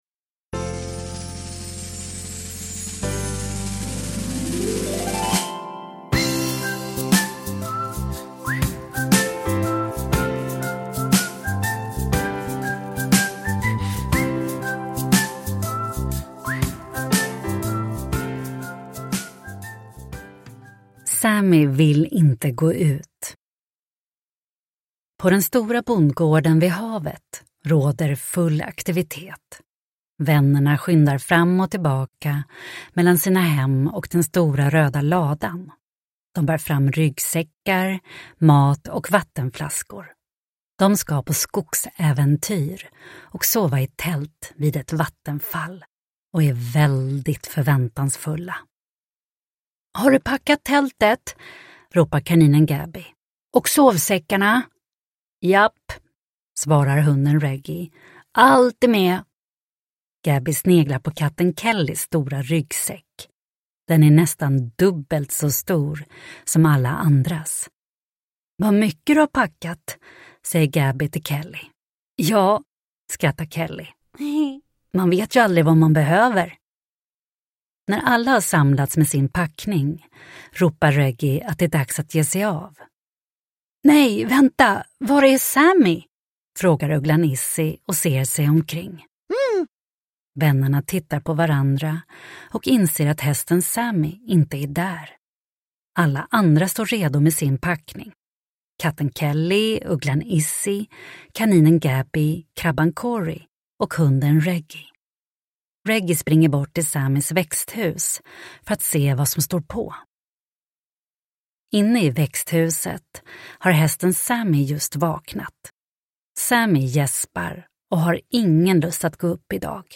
Sammy vill inte gå ut – Ljudbok – Laddas ner